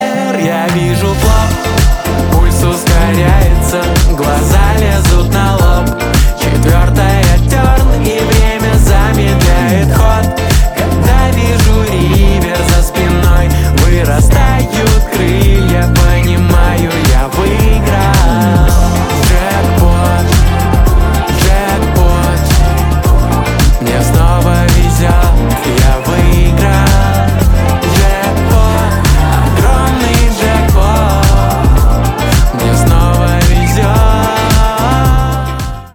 поп
веселые